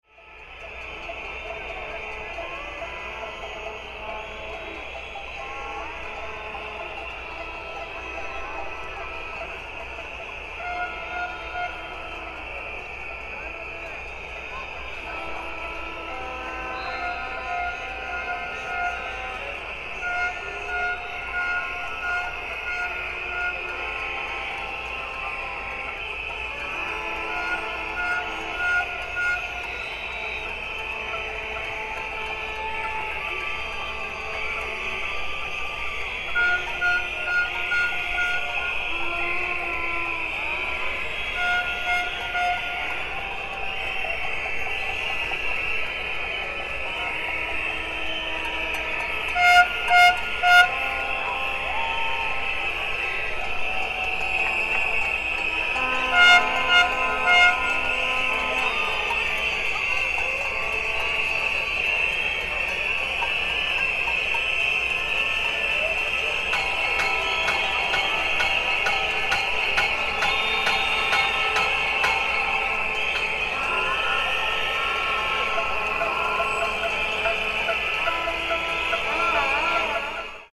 Loud Crowd Of Protesters Capturing Political Anger
Loud Crowd Of Protesters Approaching Sound Effect
A large crowd of tens of thousands of people is approaching. They express political anger by shouting, whistling, and banging. The sound captures the intense atmosphere of a loud protest or demonstration.
Loud-crowd-of-protesters-approaching-sound-effect.mp3